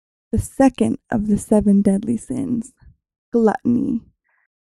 描述：女性，口语，七宗罪
Tag: 7 致命的 口语